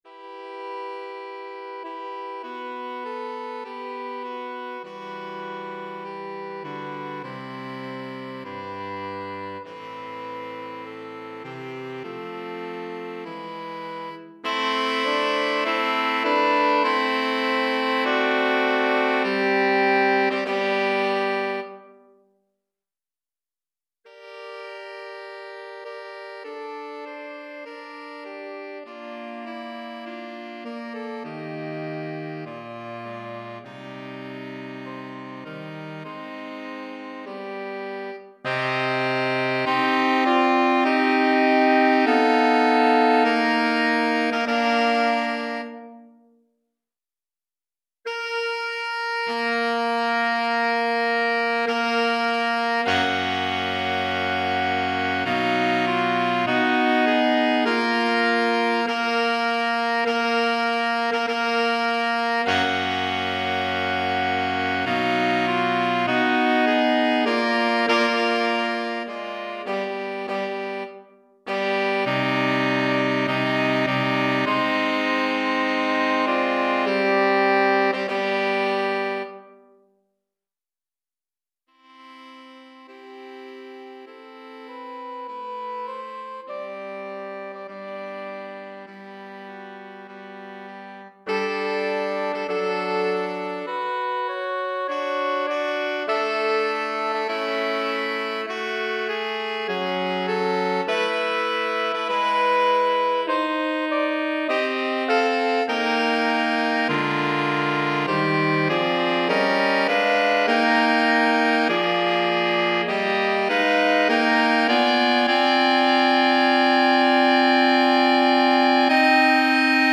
4 Saxophones